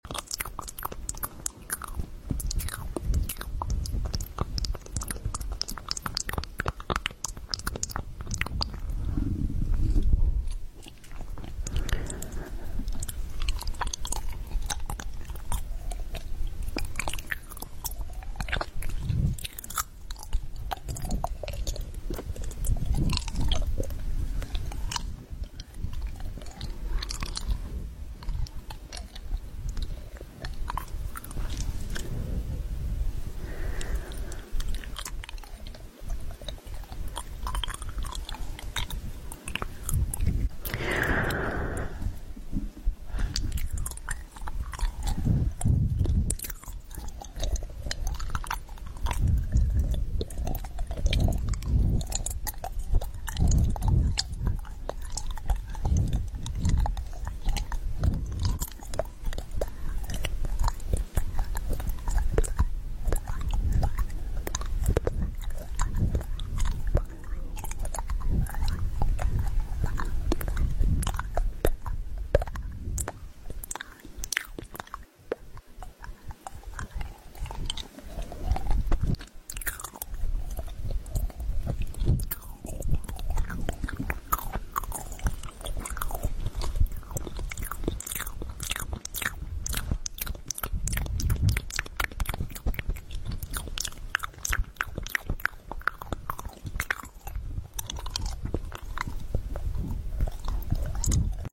Mouth sounds 👄(SLEEPY SOUNDS ASMR)👄